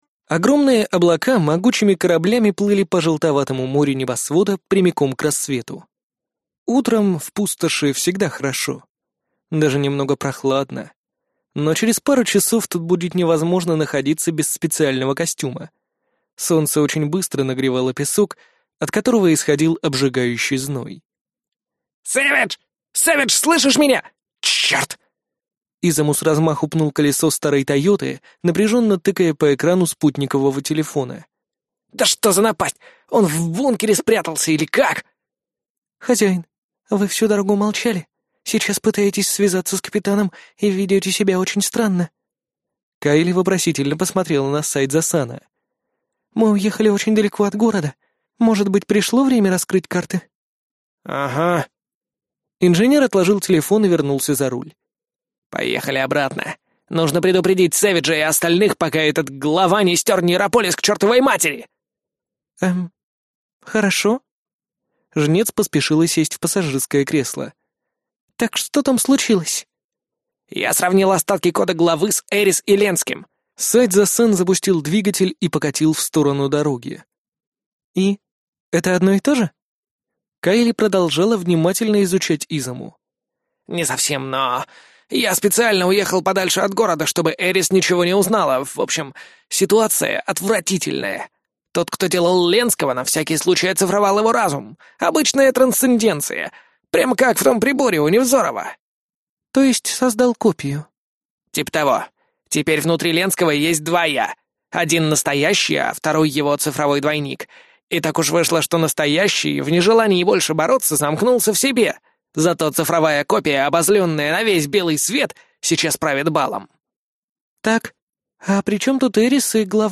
Аудиокнига Миротворец | Библиотека аудиокниг